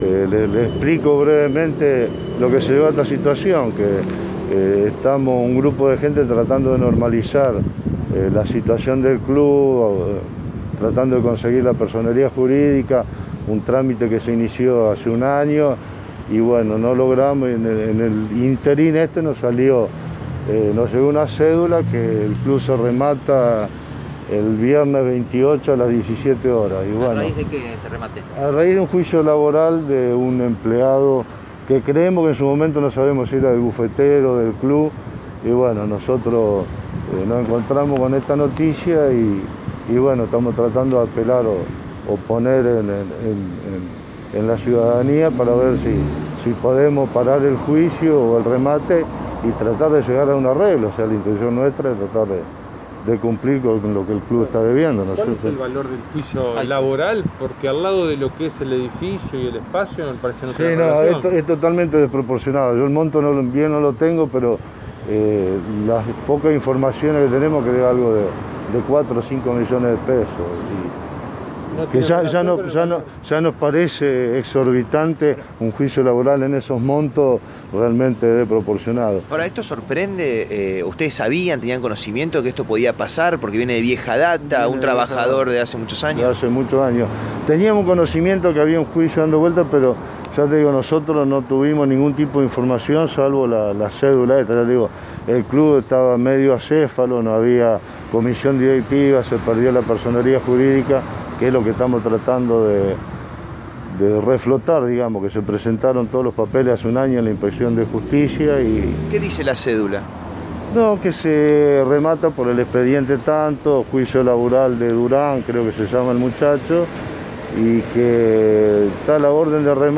En diálogo con Radio EME